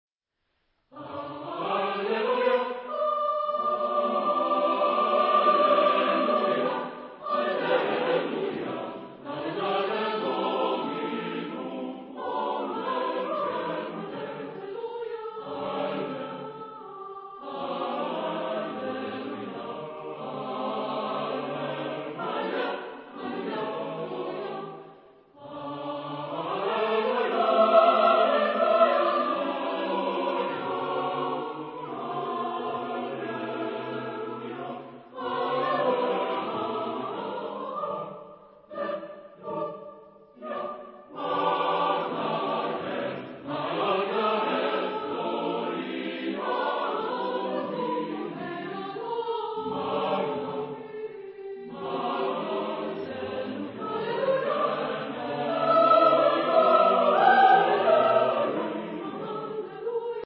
Genre-Style-Forme : Motet ; Sacré ; contemporain
Type de choeur : SATB  (3 voix mixtes )
Tonalité : tonal avec variations modales